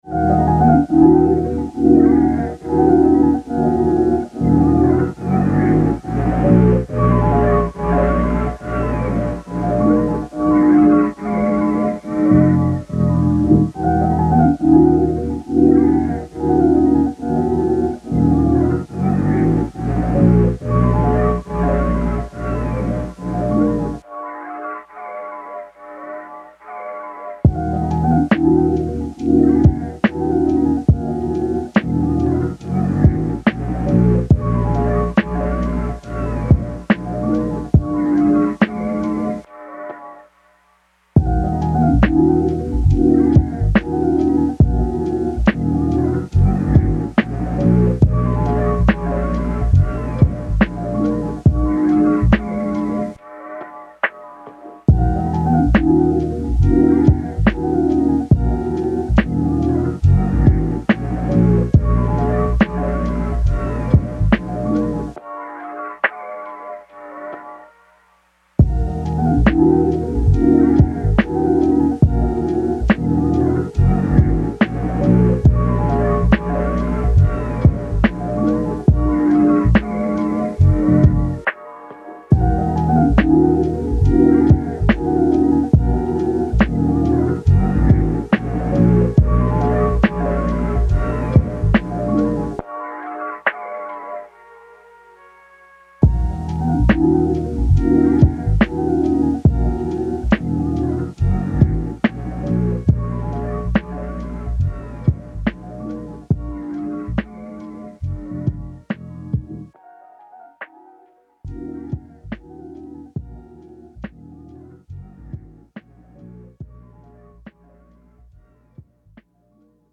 Chez soi, loin du bruit · ambiance cozy pour étudier en douceur
Aucun bruit parasite, aucune coupure soudaine.